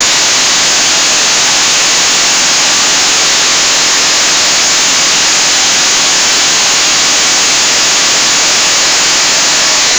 Build tension, then end with a hard cut to silence. 0:10 hard interpolated noisy television noise, with demonic shout 0:10 Charging lightning 0:01
hard-interpolated-noisy-t-fttmenkd.wav